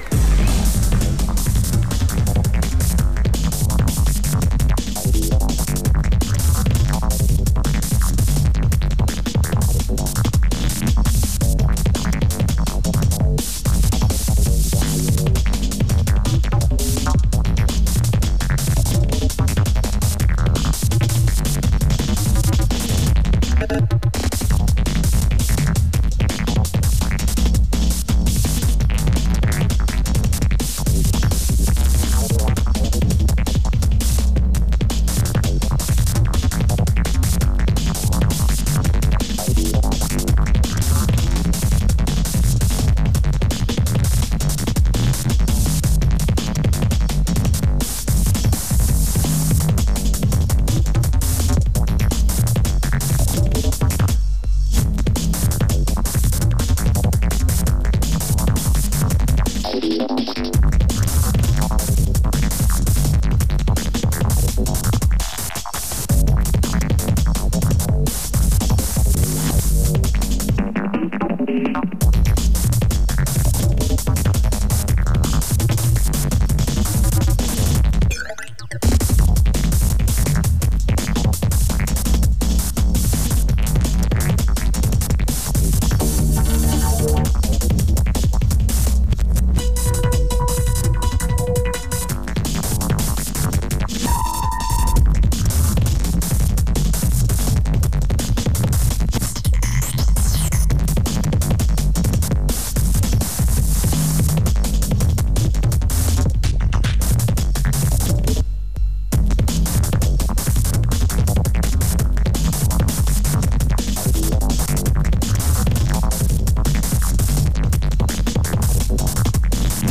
Liveset 1.